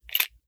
38 SPL Revolver - Loading 003.wav